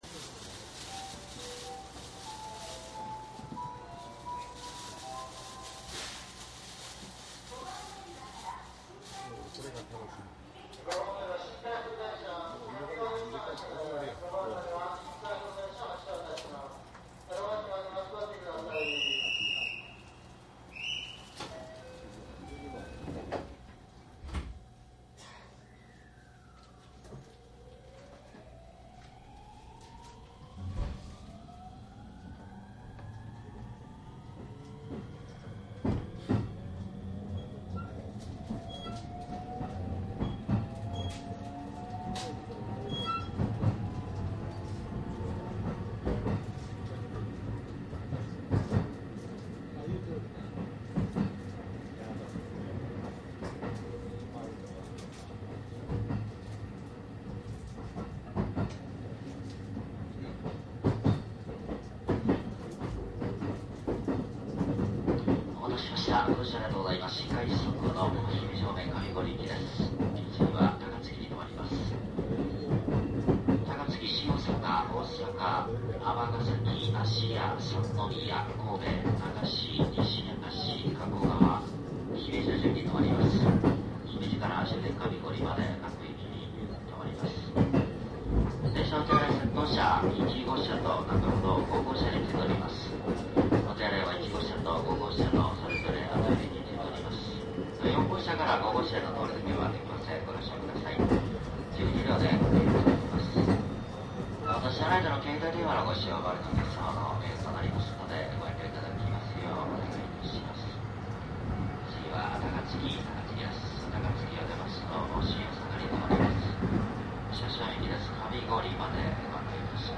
内容はJR西日本で223系新快速の走行音を録音したものになります。
マイクECM959です。DATかMDの通常SPモードで録音。
実際に乗客が居る車内で録音しています。貸切ではありませんので乗客の会話やが全くないわけではありません。